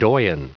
Prononciation du mot doyen en anglais (fichier audio)
Prononciation du mot : doyen